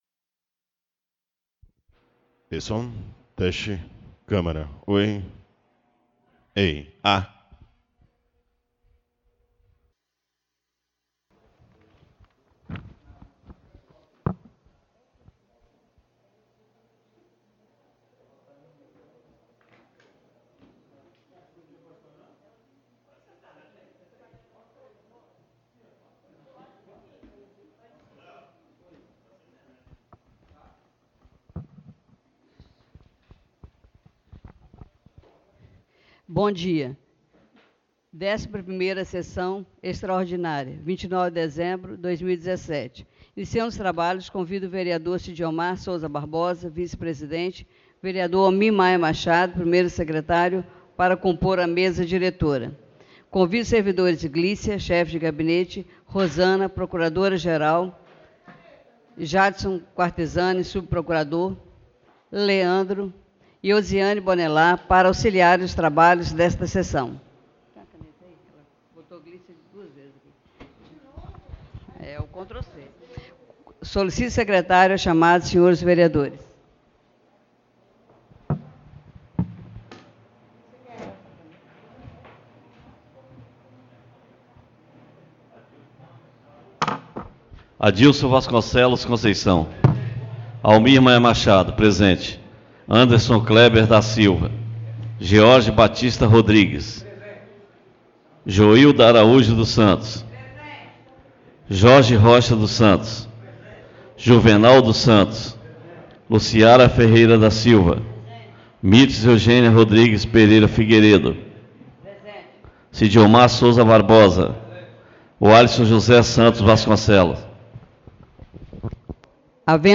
11ª (DÉCIMA PRIMEIRA) SESSÃO EXTRAORDINÁRIA DIA 29 DE DEZEMBRO DE 2017 BRAÇO DO RIO